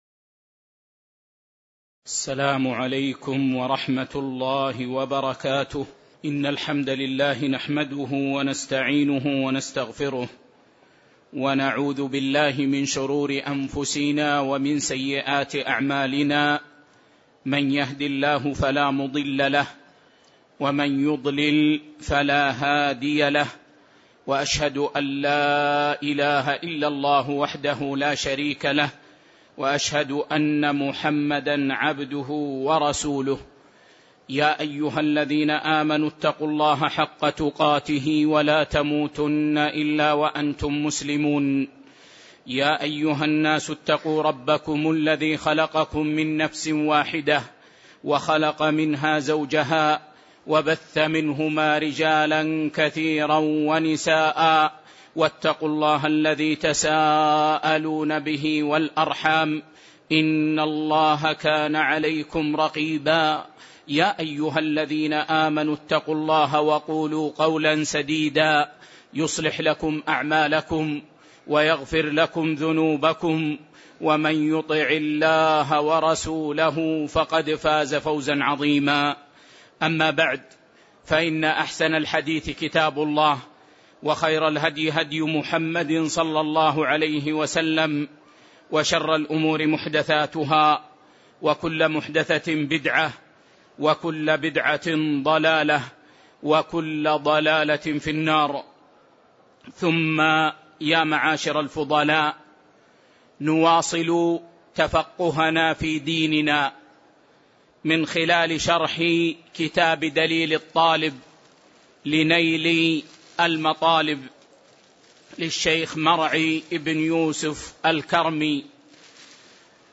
تاريخ النشر ٢٤ صفر ١٤٣٨ هـ المكان: المسجد النبوي الشيخ